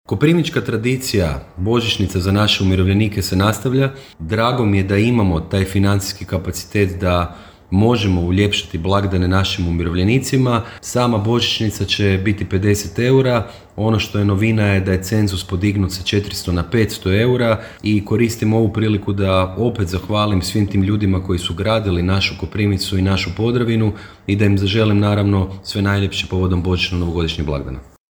-rekao je gradonačelnik Mišel Jakšić.